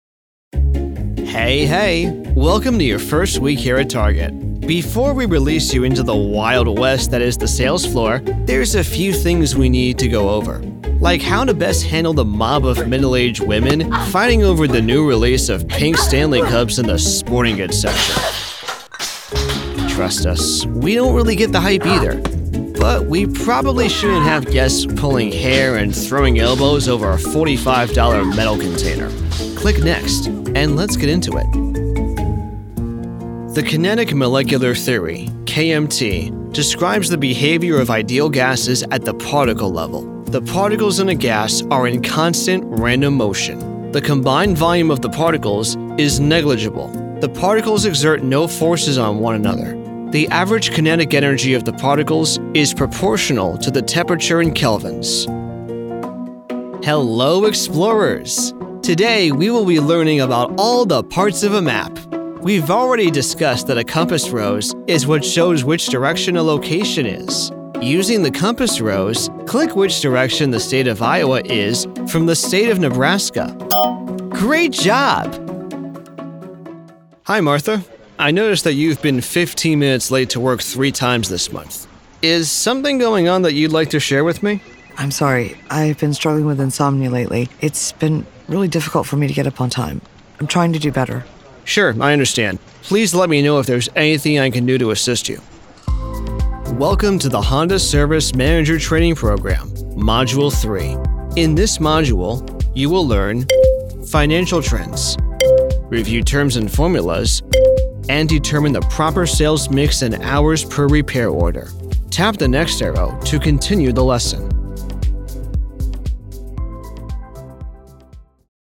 eLearning Demo
English-North American, English-Neutral
Young Adult